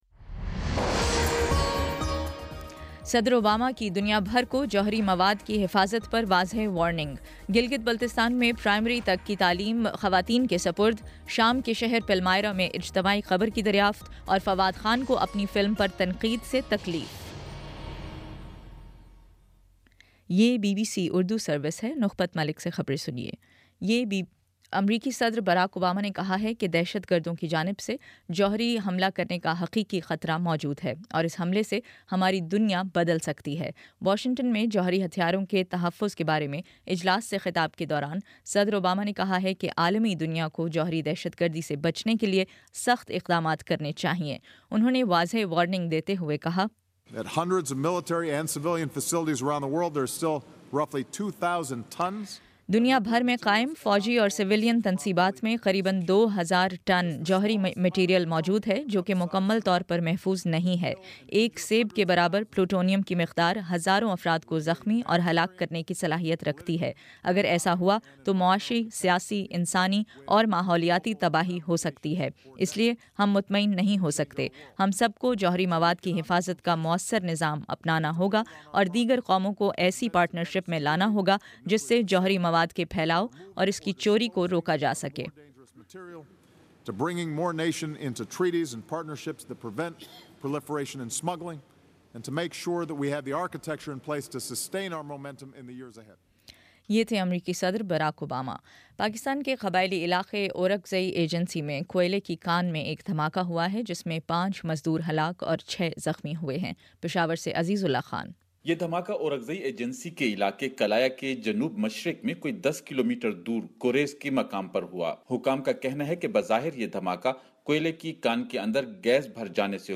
اپریل 02 : شام پانچ بجے کا نیوز بُلیٹن